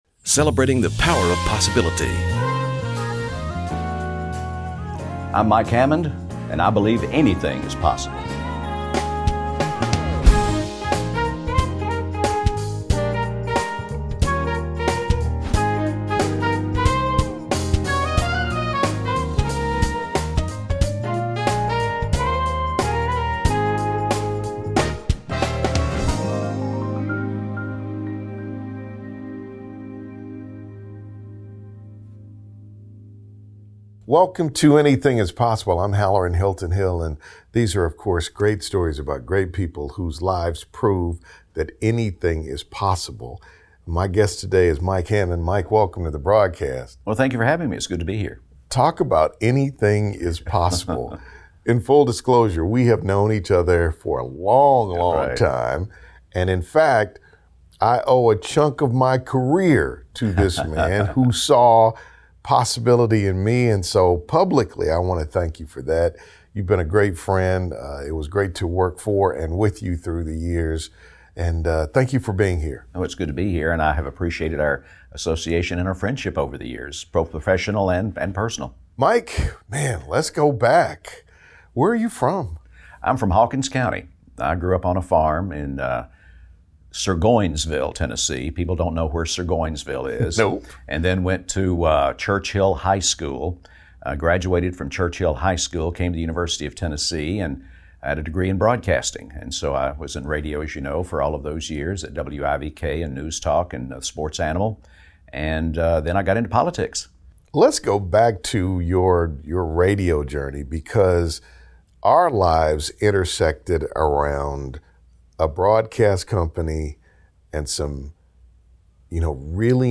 After growing up on a farm. Knox County Criminal Court Clerk, Mike Hammond invested well over 4 decades in the Radio Broadcasting industry, where he had a very rewarding and an extremely successful career.